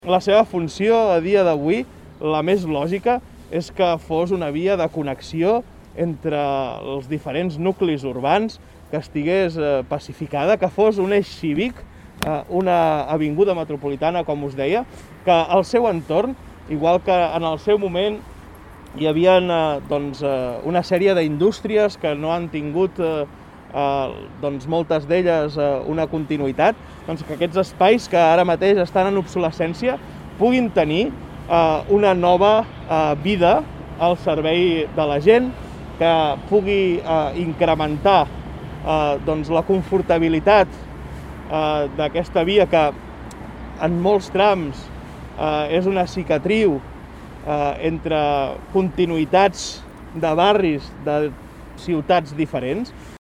Per escoltar declaracions de Juli Fernández: